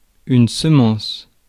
Ääntäminen
US
IPA : /ˈsiːmən/